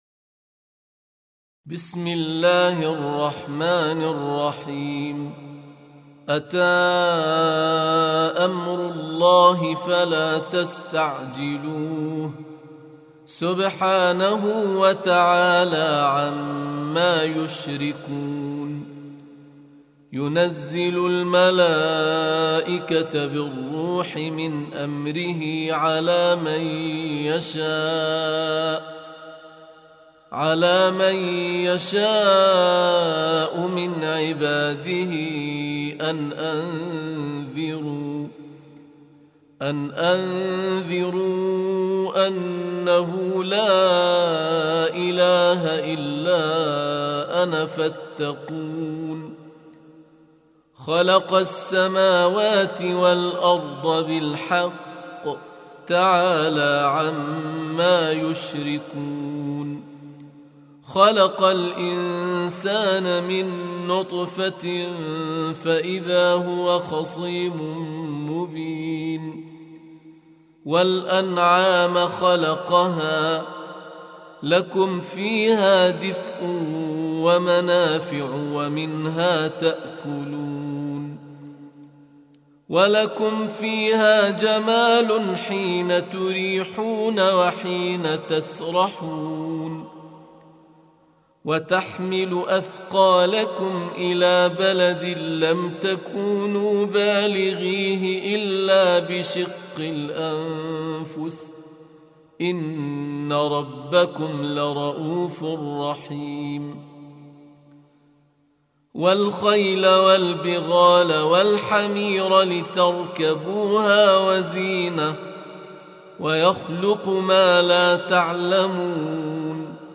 Surah Repeating تكرار السورة Download Surah حمّل السورة Reciting Murattalah Audio for 16.